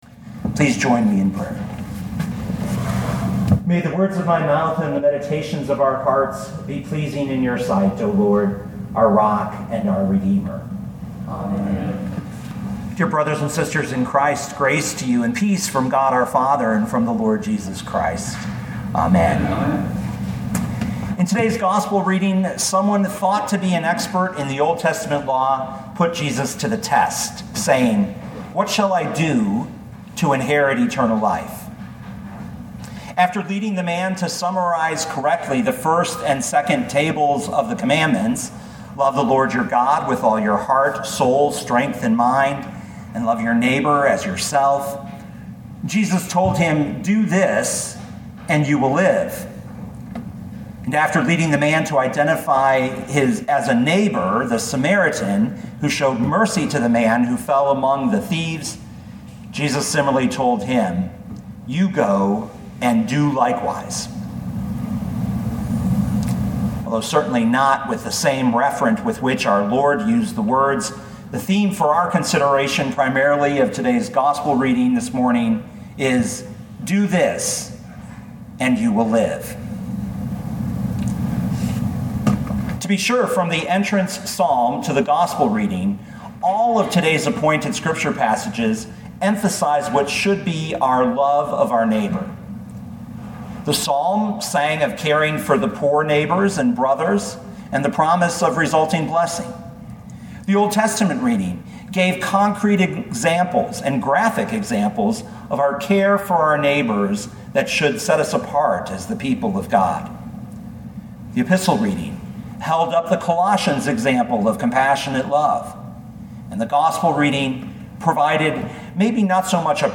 2019 Luke 10:25-37 Listen to the sermon with the player below, or, download the audio.